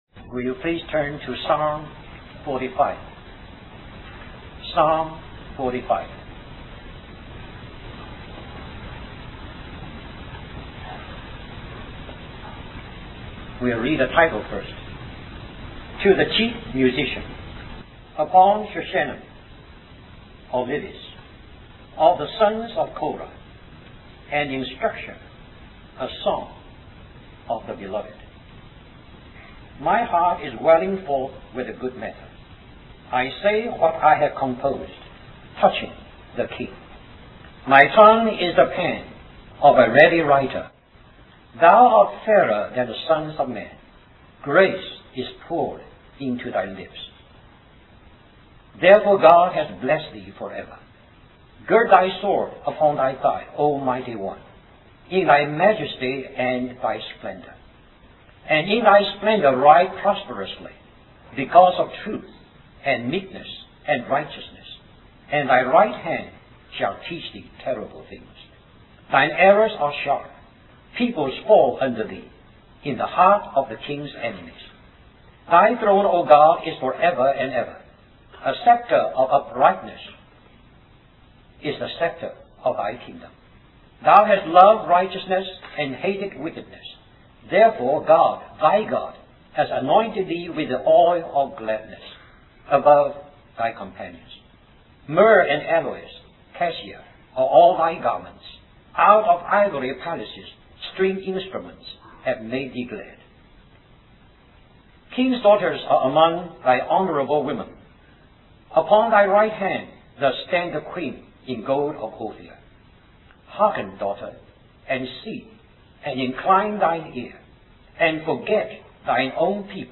A collection of Christ focused messages published by the Christian Testimony Ministry in Richmond, VA.
1993 Christian Family Conference Stream or download mp3 Topics Purpose Scriptures Referenced Song of Solomon 7:10 10 I am my beloved’s